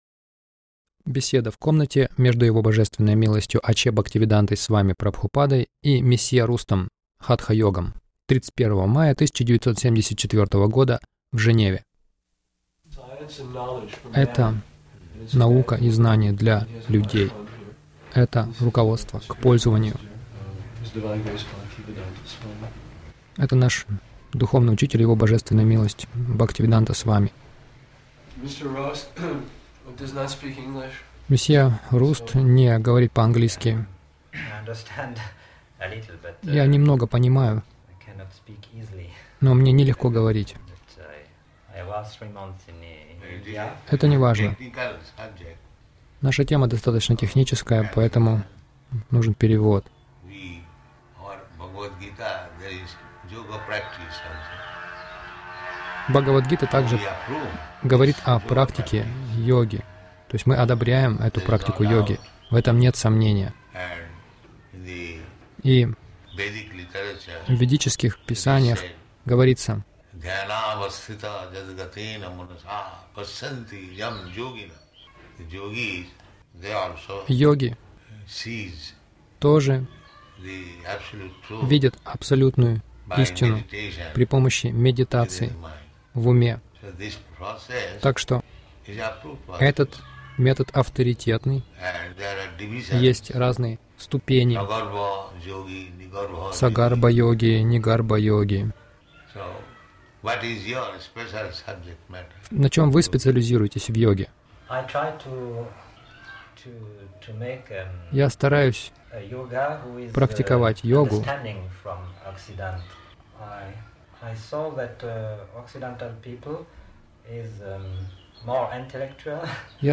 Беседа в комнате — Авторитетная практика йоги